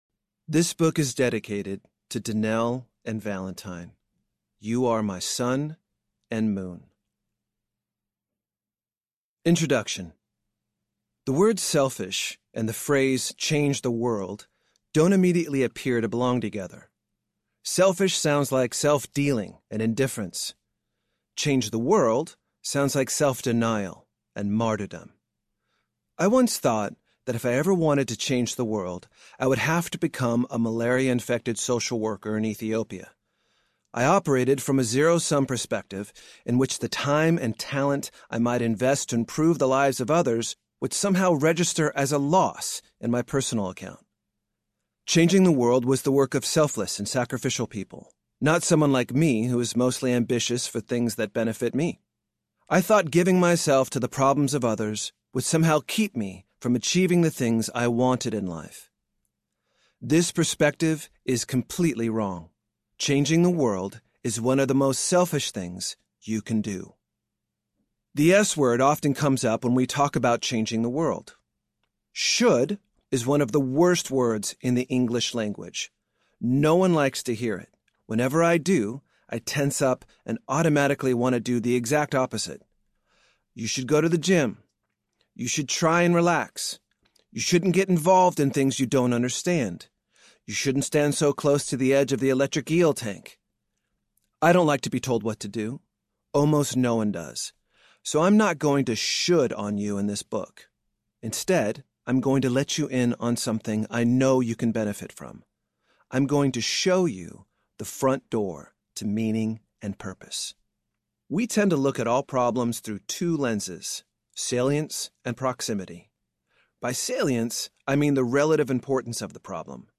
A Selfish Plan to Change the World Audiobook
8.5 Hrs. – Unabridged